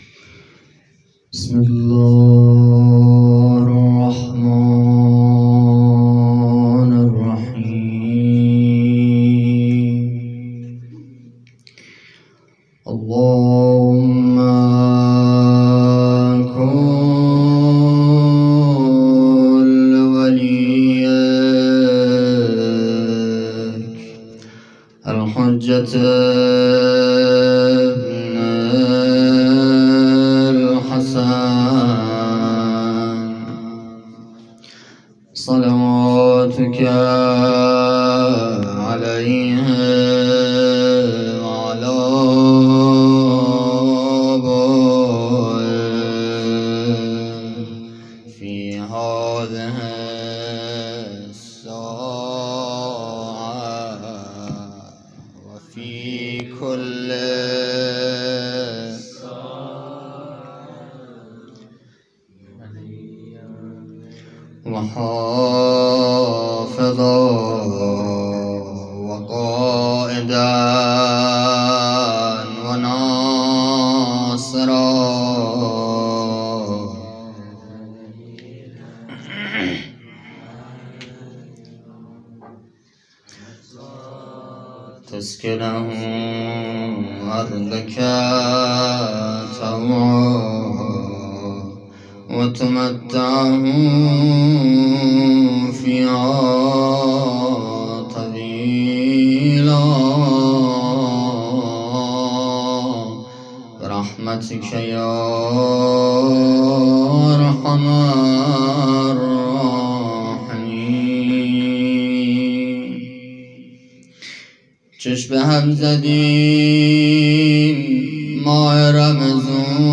[فایل صوتی] - [مناجات_روضه] - [بزرگداشت ائمه بقیع(ع)] - ای مهربان عمری مرا..